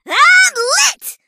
bonni_ulti_vo_07.ogg